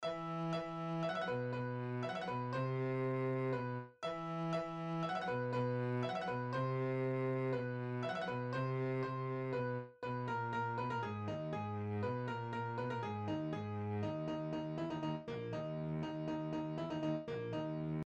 Kniha obsahuje notový zápis „vlčí písně“, a protože jsme sami chtěli slyšet, jak taková píseň zní, zkusili jsme ji převést do zvukové podoby.
WolfSong.mp3